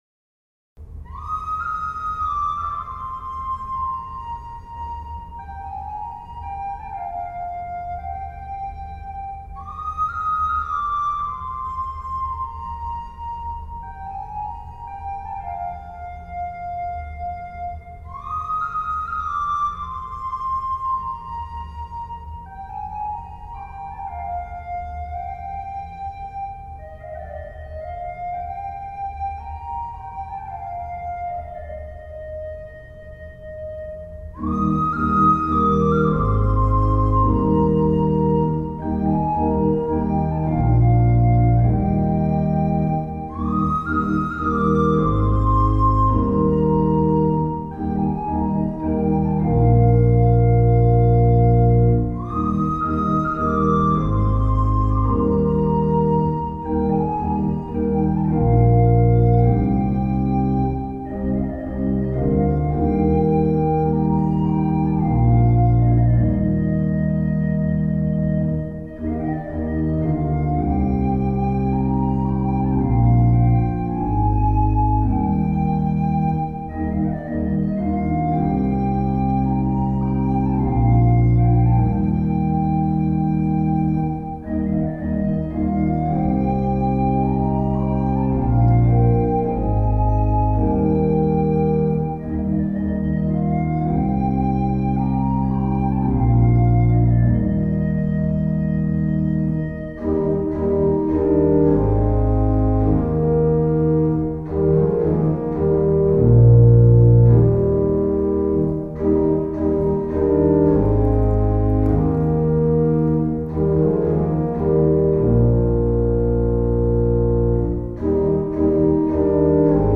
Aktuelle Probenaufnahmen: